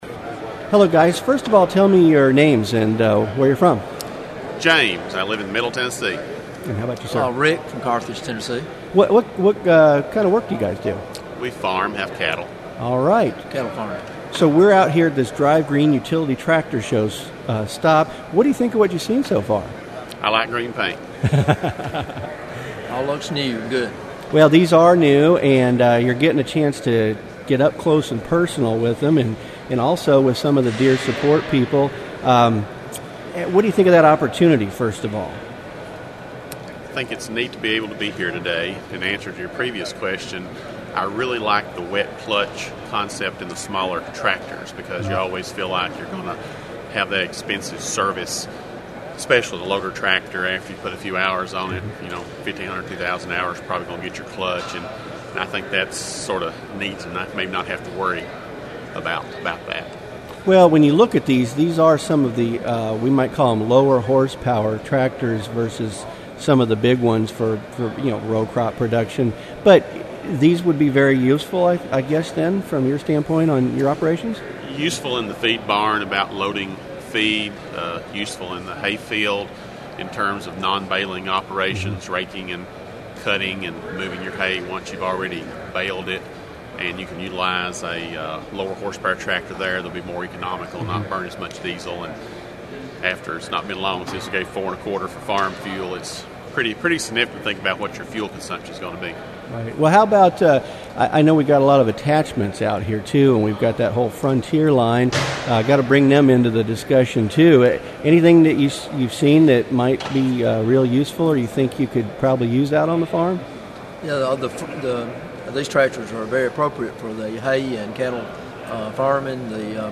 I spoke with them on the floor of the Coliseum about how useful these utility tractors would be for their operations.